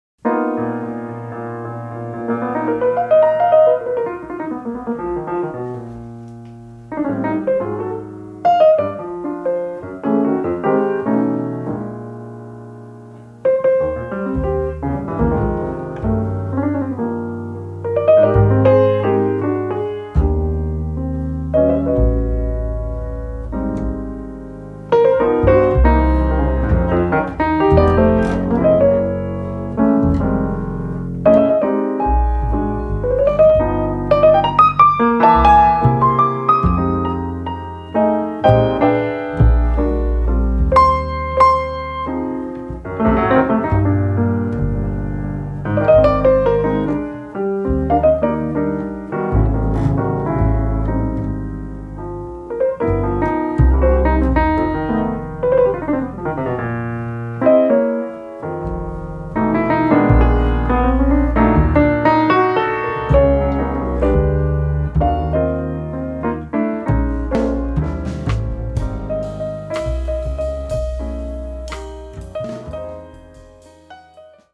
Recorded and mixed in Bergamo, Italy, in December 2008
pianoforte
contrabbasso
batteria
acquista una nuova ed inaspettata veste ritmica.